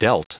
Prononciation du mot dealt en anglais (fichier audio)
dealt.wav